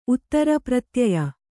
♪ uttara pratyaya